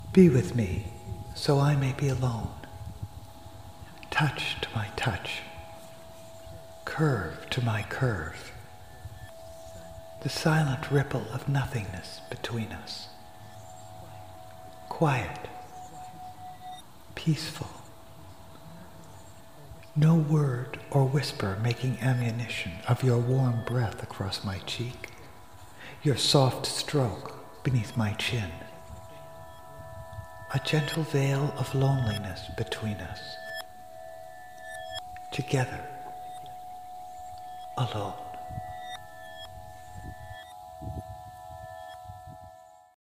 Be-With-Me-Vox-Fx-Music-Mixes.mp3